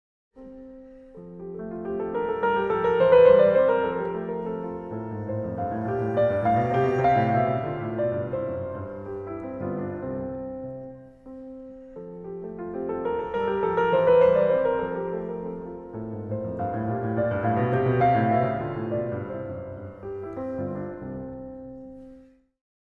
Here, the beginning is the first one which has a more “given-up” character.
The middle part, which is called the trio is in a contrasting character.
op2no1menuet2.mp3